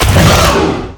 combat / ENEMY / dulfhit2.wav